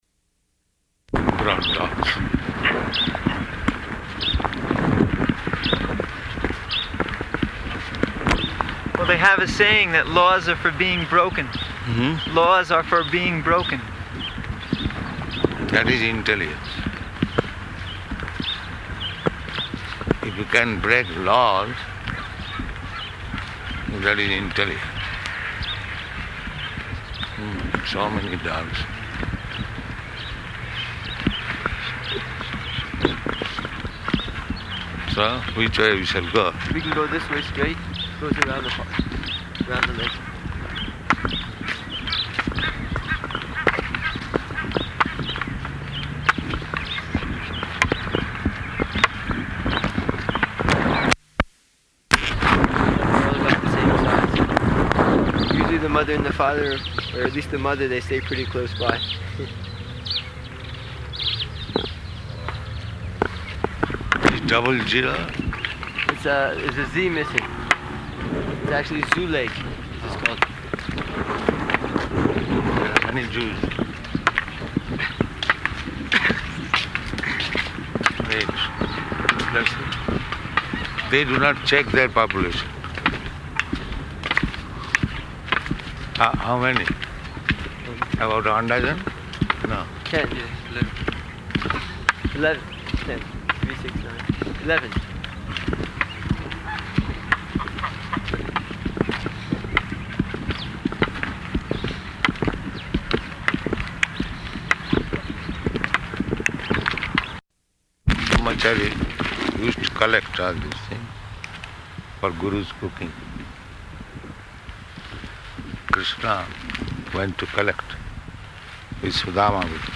Morning Walk --:-- --:-- Type: Walk Dated: October 21st 1975 Location: Johannesburg Audio file: 751021MW.JOH.mp3 Prabhupāda: Nonsense.